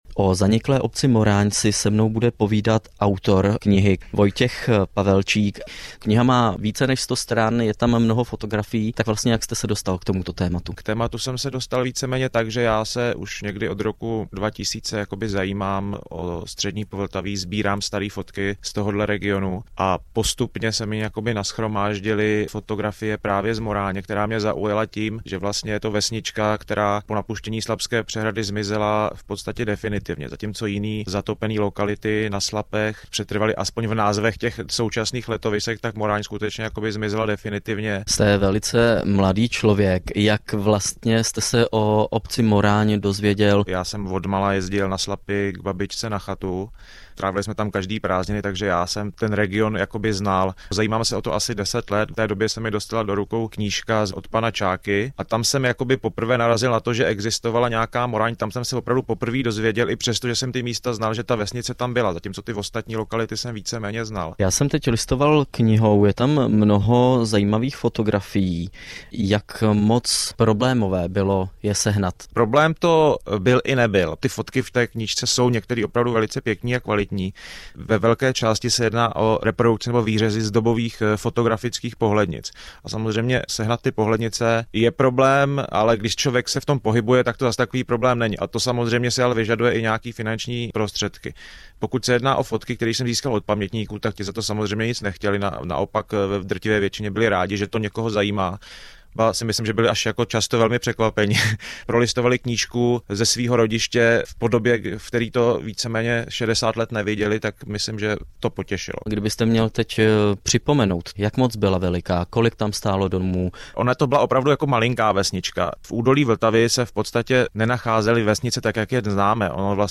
Rozhovor o knížce i o Moráni samotné.